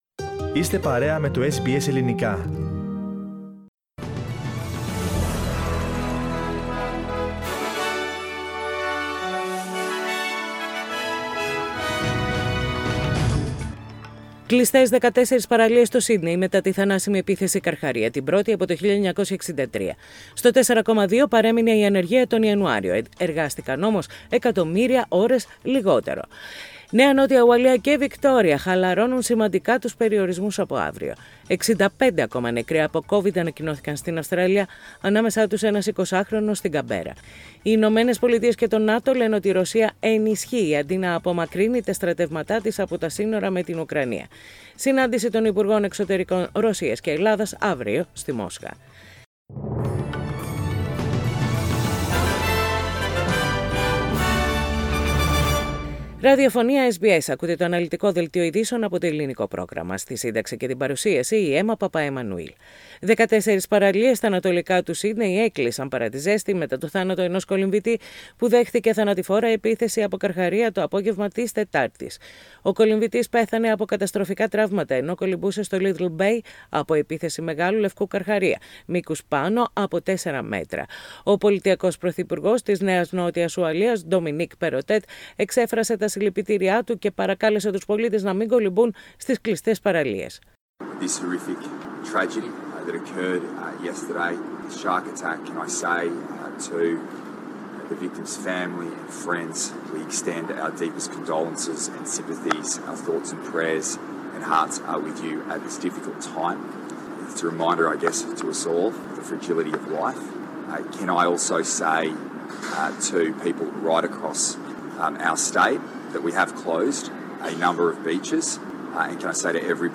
The detailed bulletin with the main news from Australia, Greece, Cyprus and the world.
News in Greek. Source: SBS Radio